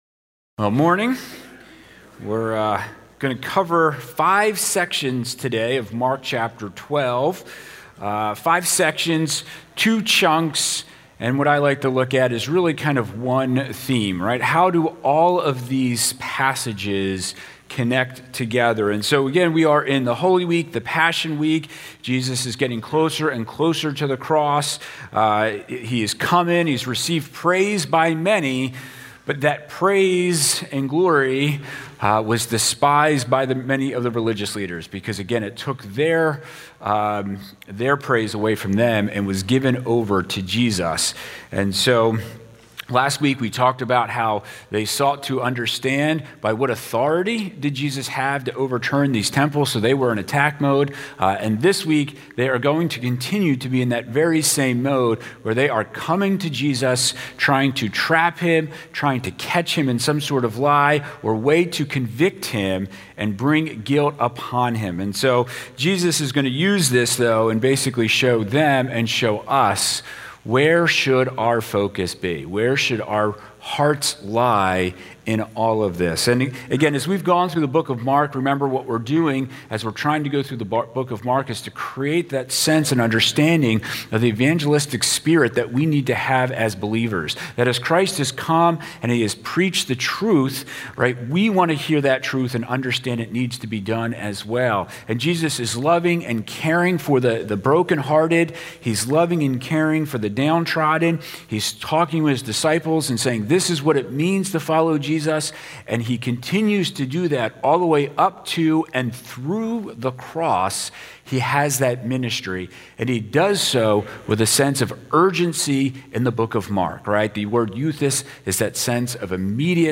Penn Valley Church Sermons